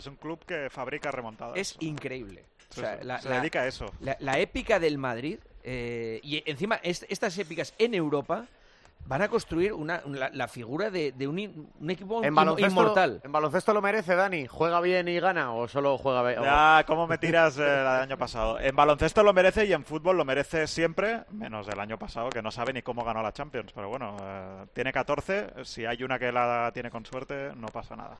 El presentador de 'El Partidazo de COPE' destaca el ímpetu de las secciones del equipo de baloncesto y de fútbol tras lo logrado en la Euroliga este miércoles